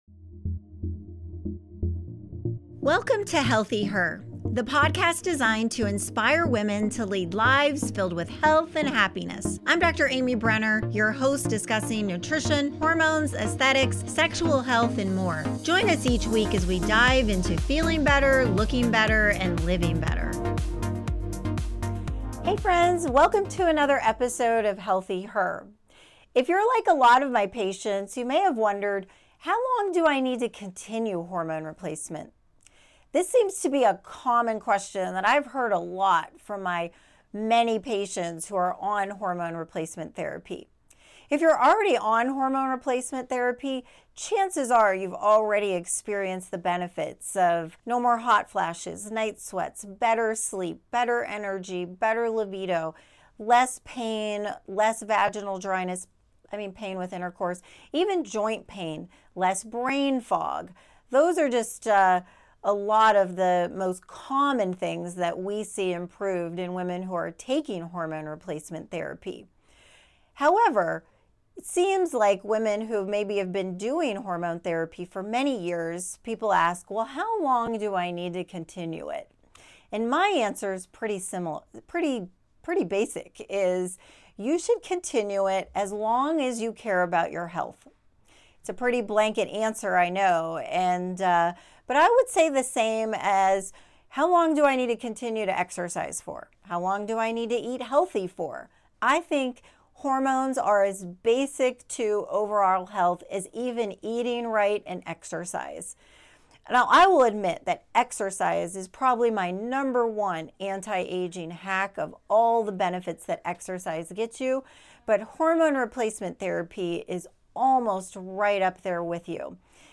#96 - How Long Should a Woman be on Hormone Therapy? Mini Solocast